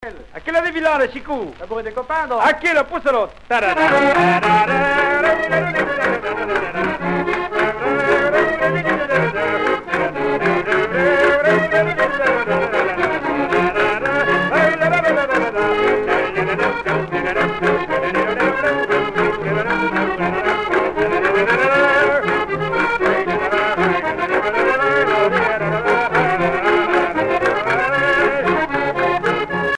danse : bourree ;
Pièce musicale éditée